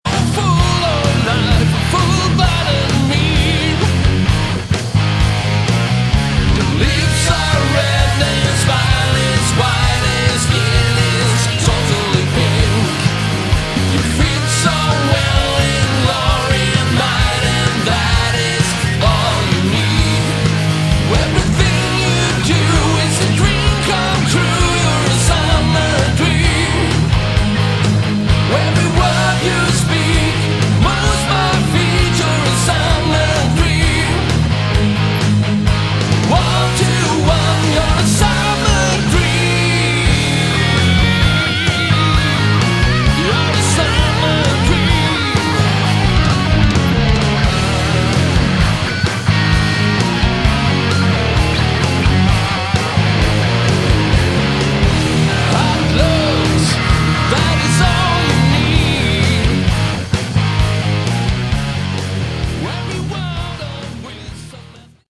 Category: Hard Rock
vocals, guitars
bass